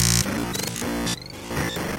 دانلود آهنگ لگ از افکت صوتی اشیاء
دانلود صدای لگ از ساعد نیوز با لینک مستقیم و کیفیت بالا
جلوه های صوتی